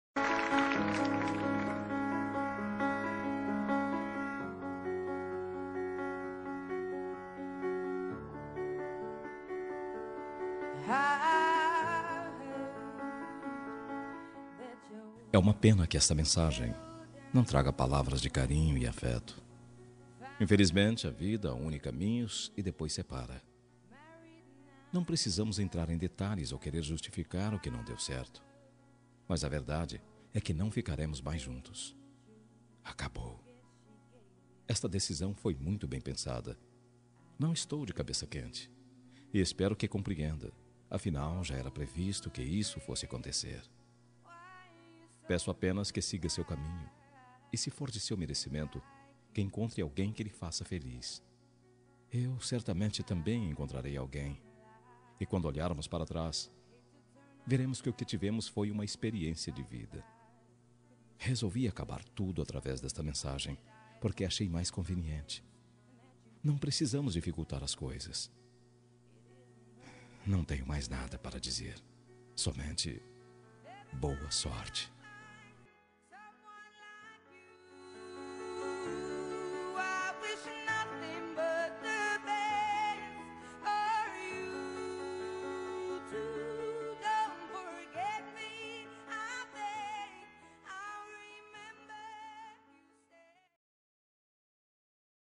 Término – Voz Masculina – Código: 8664